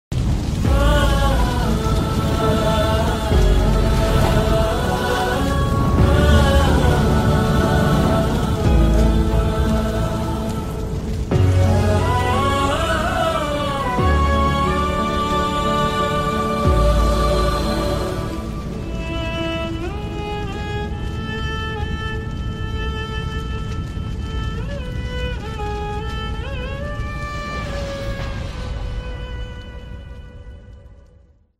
soulful background music